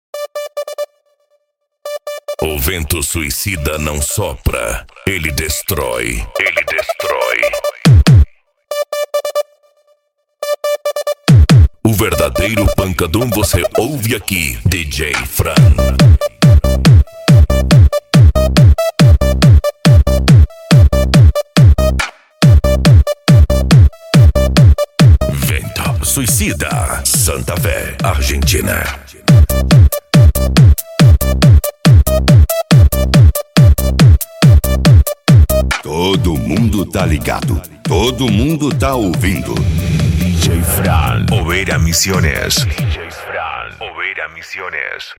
Bass
PANCADÃO
Psy Trance
Racha De Som